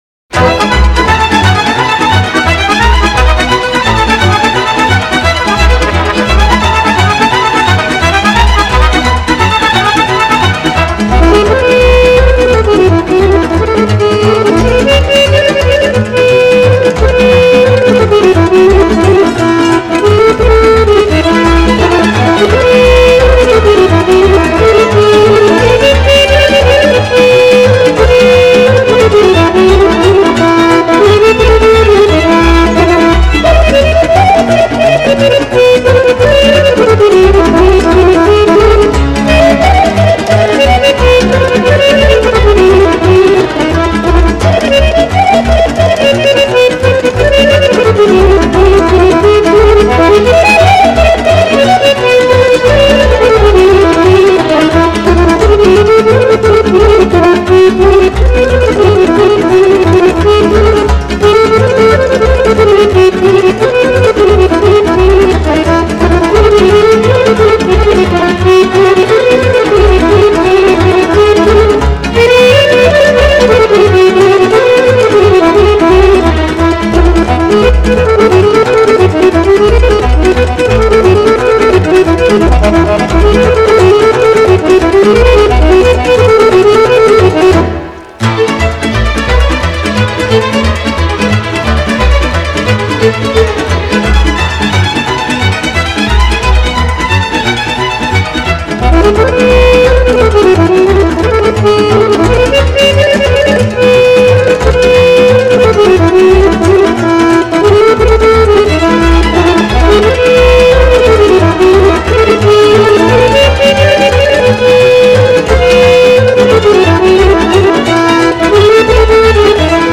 Играет молдавский аккордеонист
Народные песни и танцы